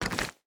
Pick Up Stone A.wav